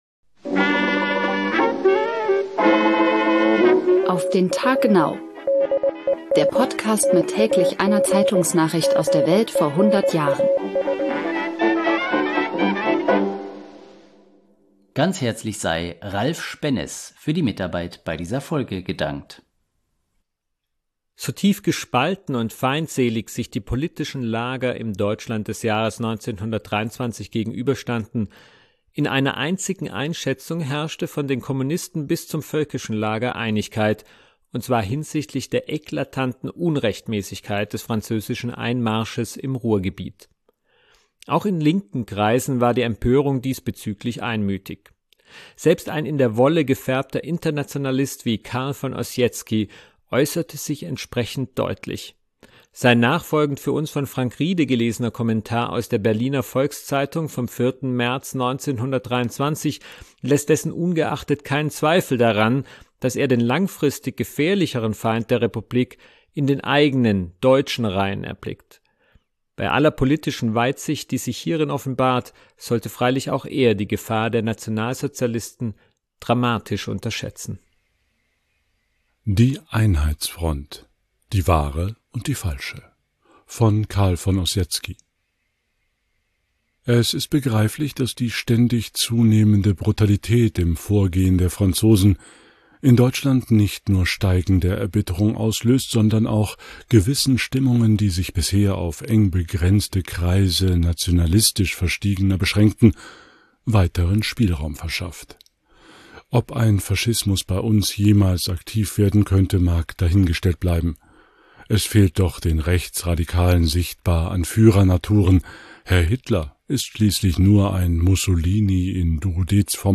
gelesener Kommentar aus der Berliner Volks-Zeitung vom 4. März 1923